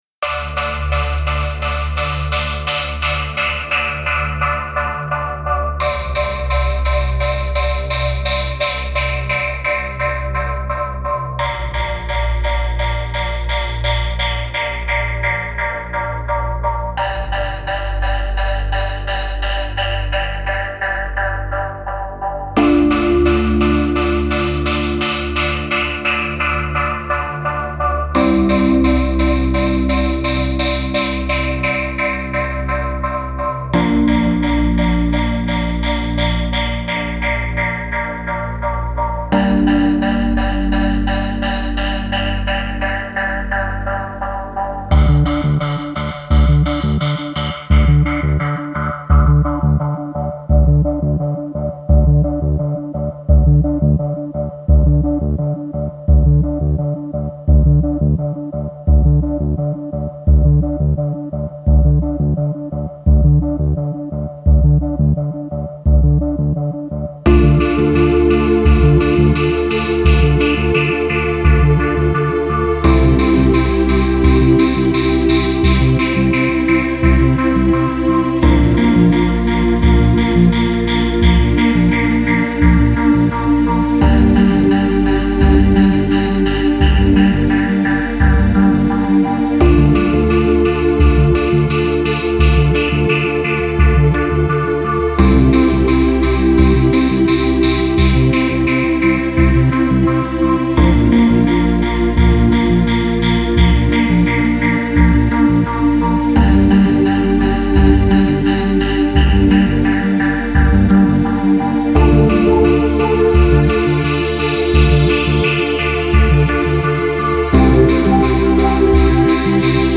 Genre: Chillout, Lounge, Downtempo.